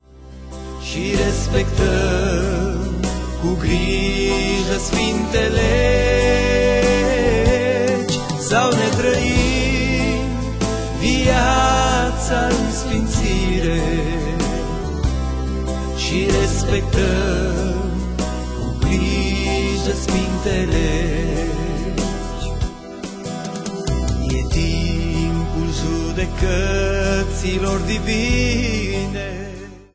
muzicii crestine romanesti